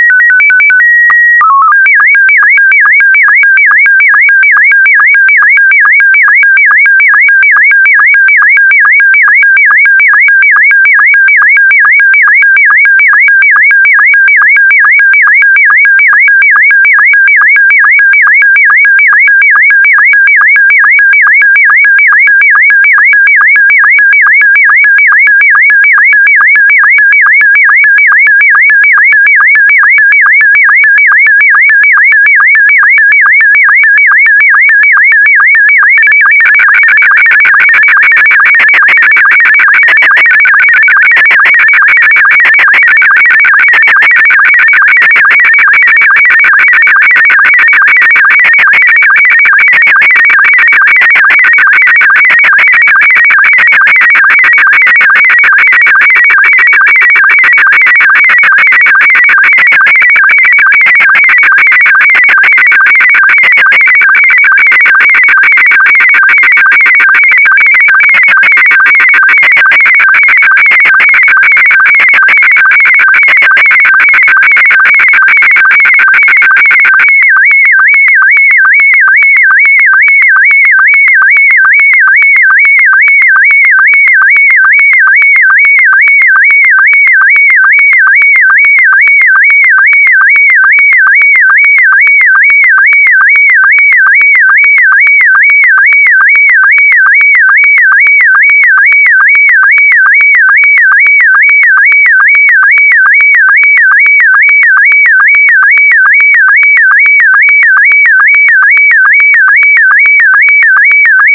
It sure does get loud though, headphone users be warned!!
The audio had this repetitive distinctive pattern, and then some distortion in the middle before returning to just the distinctive pattern - this was definitely not music. It almost reminded me of the noises modems would make dialing up to the Internet or sending a fax.
So it’s learning time now, lets do some research on audio transmission protocols and what else out there kinda sounds like a modem but isn’t… and that is when I came across SSTV (Slow Scan Television) - a method used by ham radio operators to transmit images over audio.